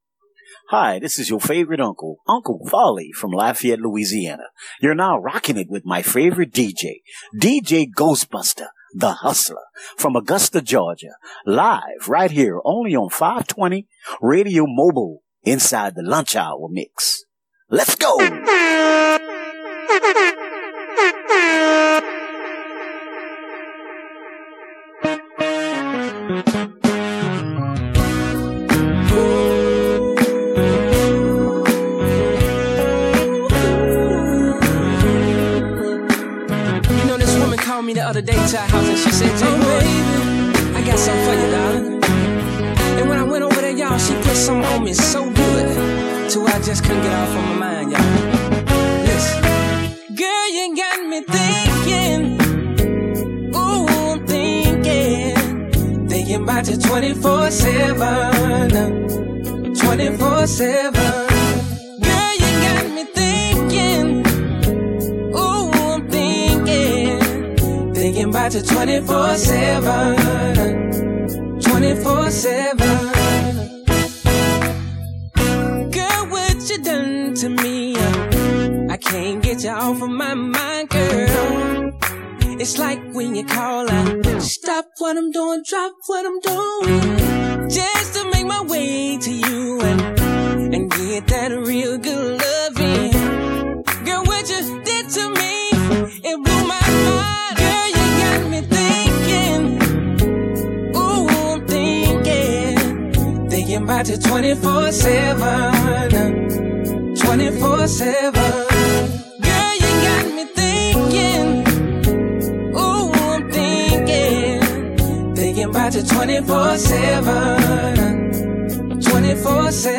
Hiphop
Blazing UnderGround New School & Old School Hip - Hop & RnB